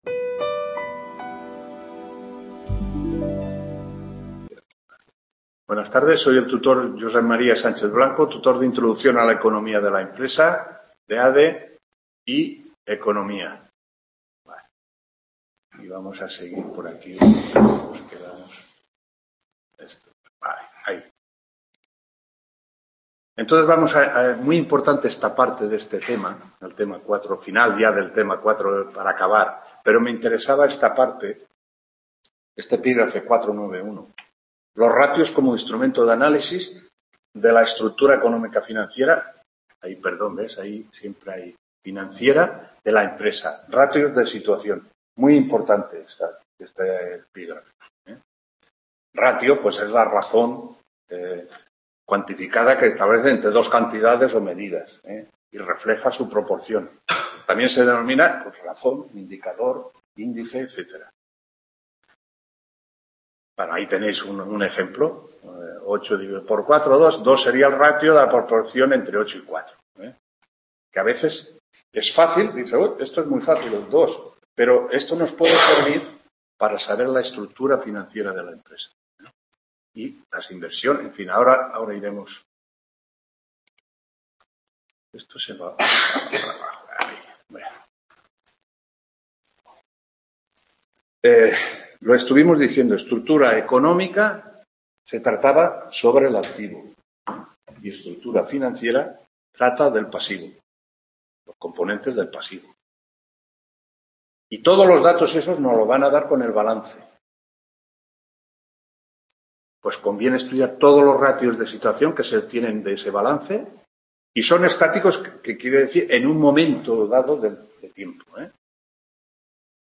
9ª TUTORÍA INTRODUCCIÓN A LA ECONOMÍA DE LA EMPRESA…